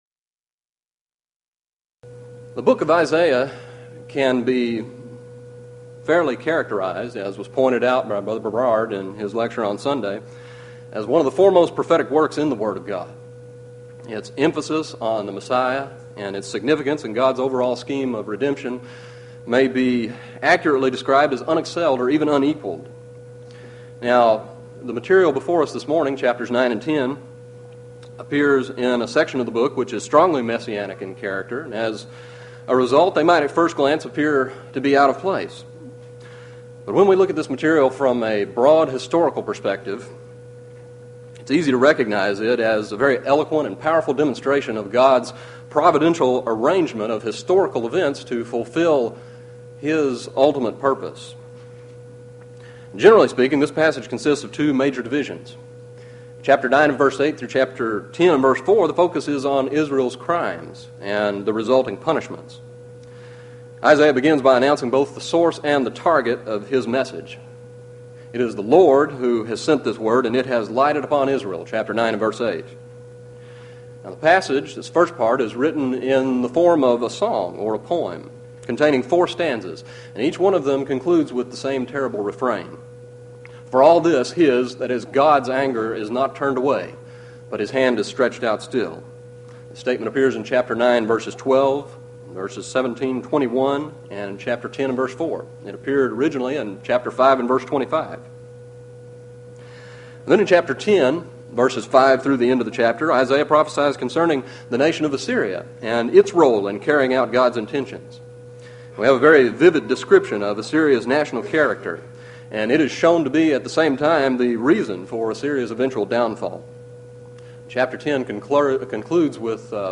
Event: 1995 HCB Lectures Theme/Title: The Book Of Isaiah - Part I